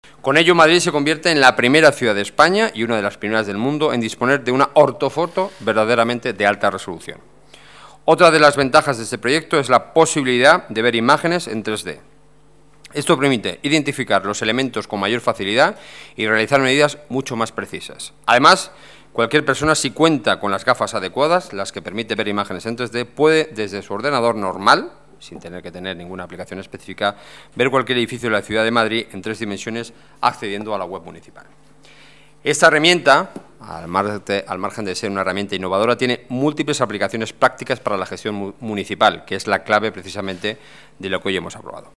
Nueva ventana:Declaraciones del portavoz del Gobierno municipal, Enrique Núñez